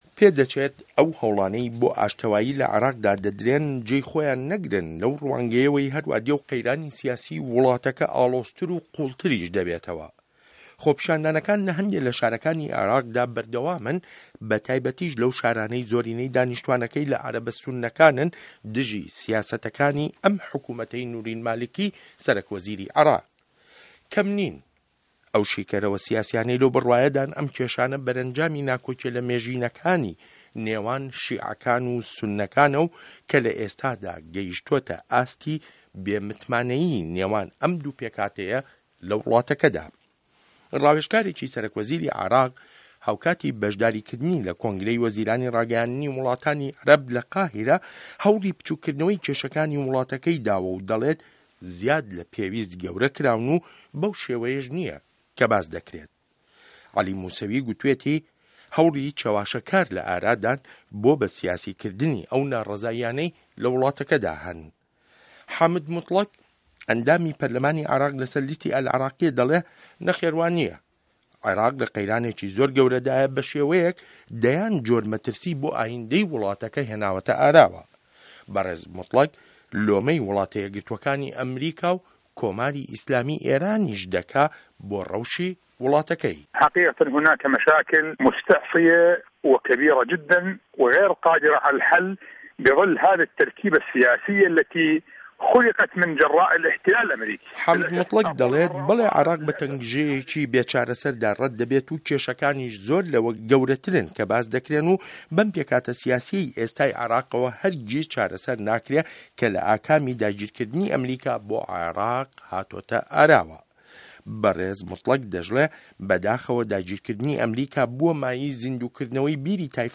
ڕاپۆرتی عێراق له‌ ڕوانگه‌ی شیعه‌یه‌ک و سوننه‌یه‌که‌وه‌